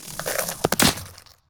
Sfx_manitsattack_02.ogg